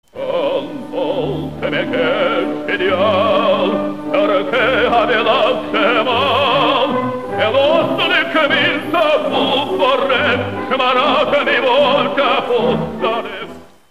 В основу этой оперы Ференц Эркель положил историю XIII века — заговор дворян Венгрии против королевы-немки, правившей страной во время военного похода мужа.